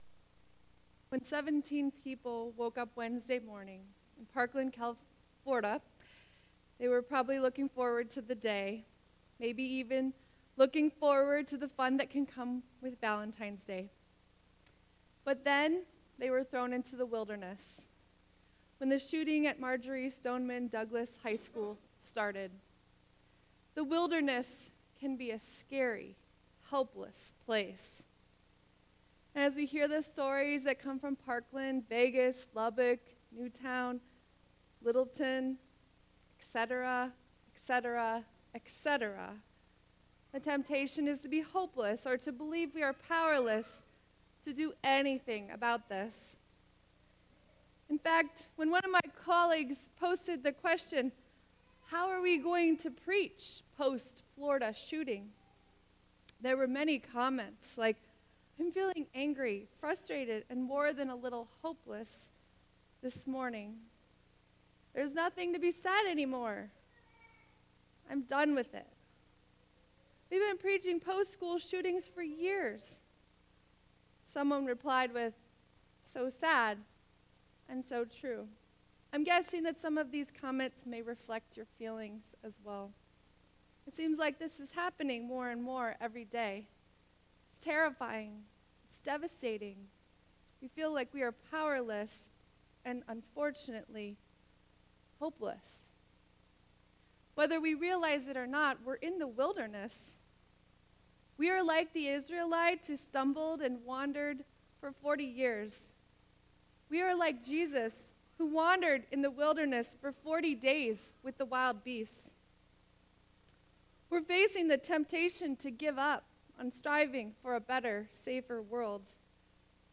Sermon 2.18.2018